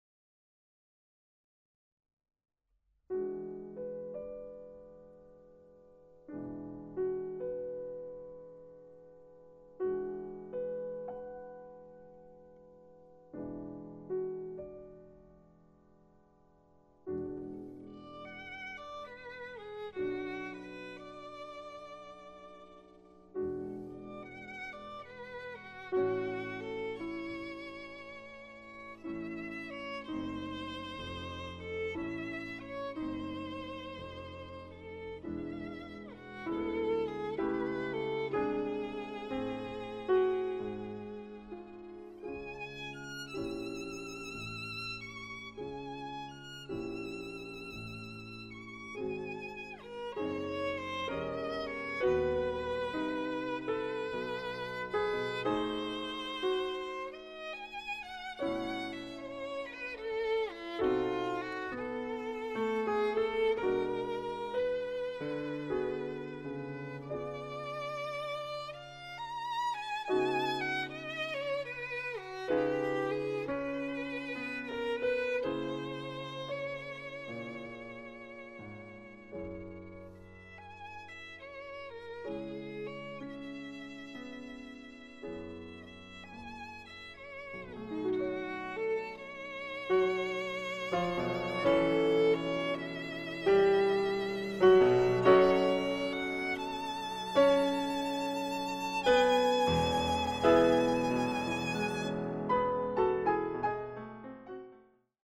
Sonate per violino e pianoforte